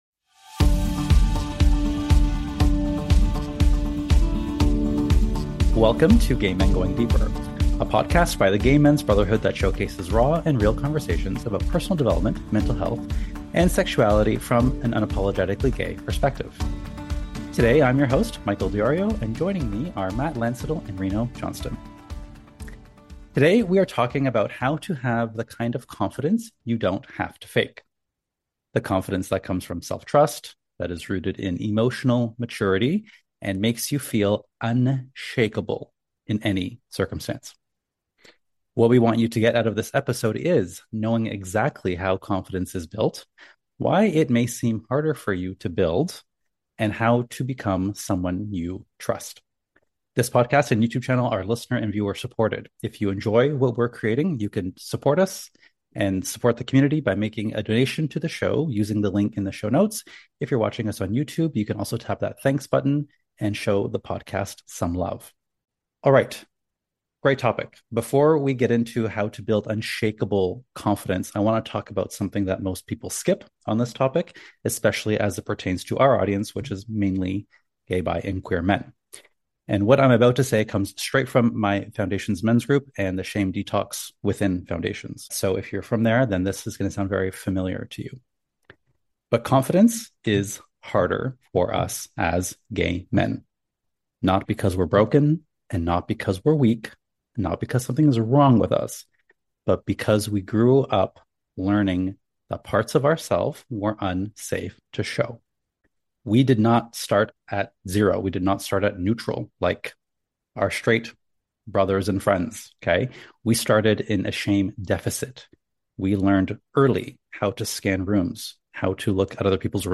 This episode of Gay Men Going Deeper is less of a casual conversation and more of a confidence masterclass.